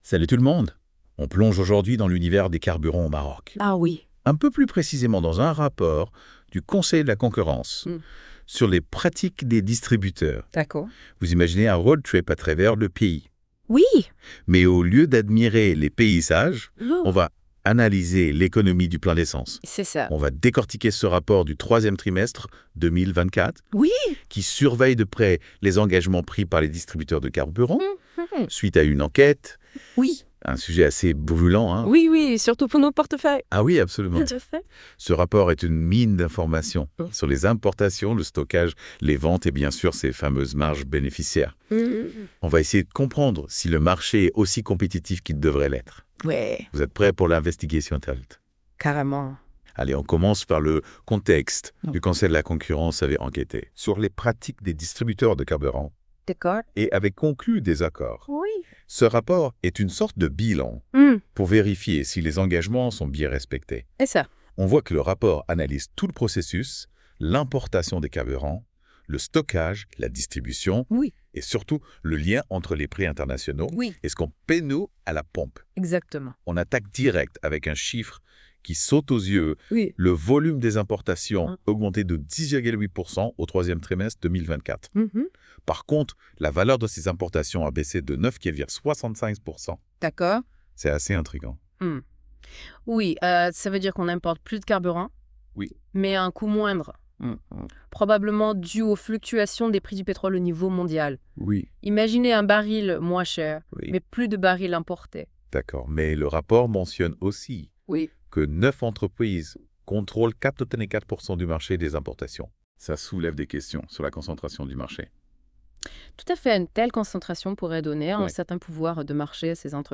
Débat (41.82 Mo) Comment le marché marocain du gasoil et de l'essence a-t-il évolué au 3ème trimestre 2024 ?